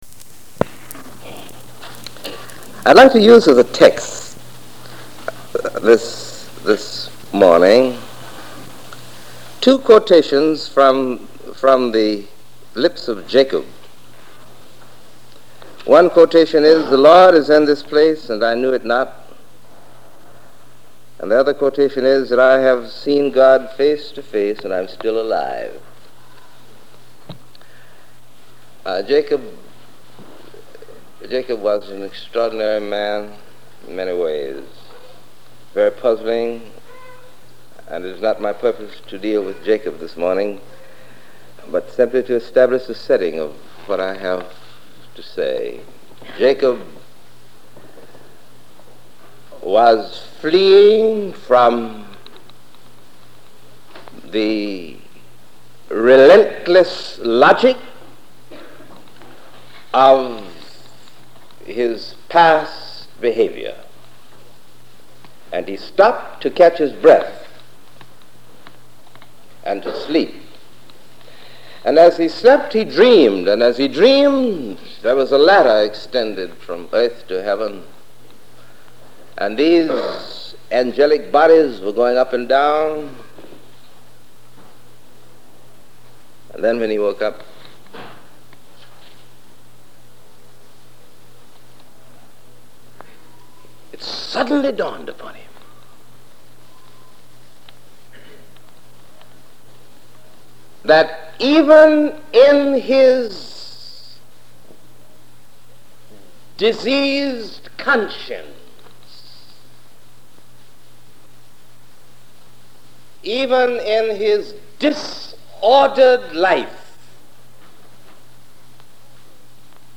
The Divine Encounter in Crisis is part one of three part sermon series. This sermon deals with how our spirtuality and belief in God can help us through difficult times in our lives.